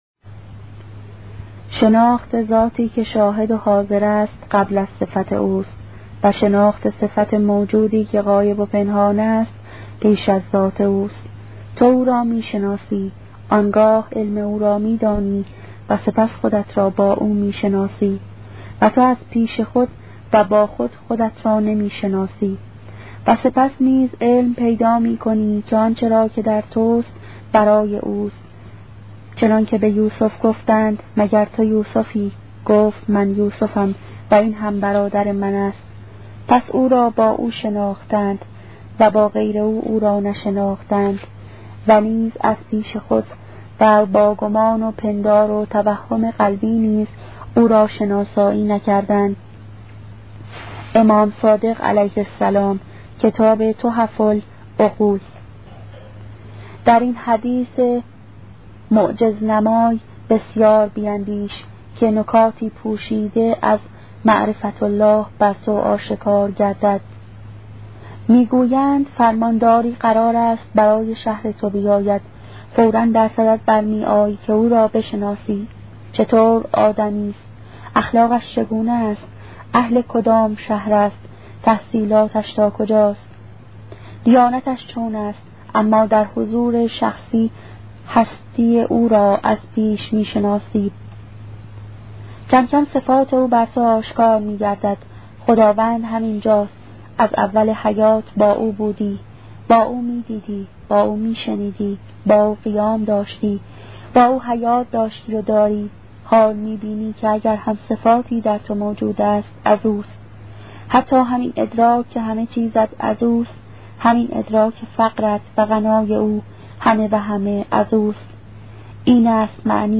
کتاب صوتی عبادت عاشقانه , قسمت چهارم